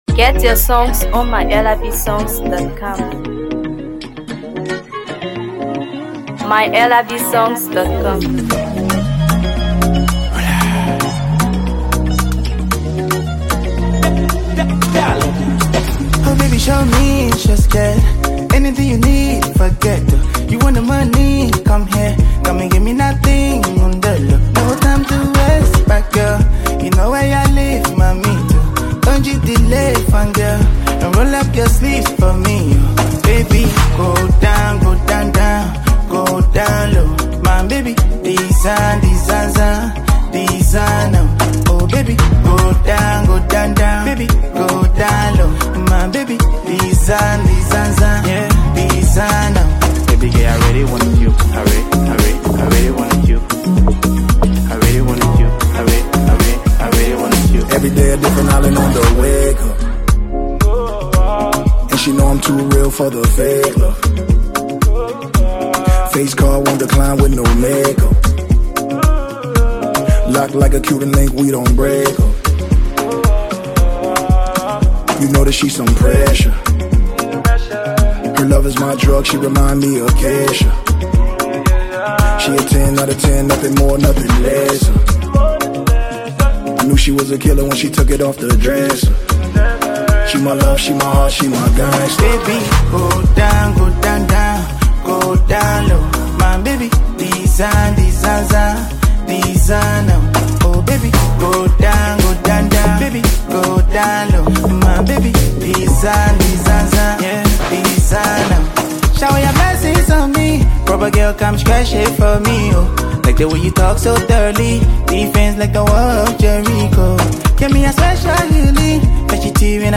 Afro PopMusic
Blending Afrobeat rhythms with hard-hitting hip-hop vibes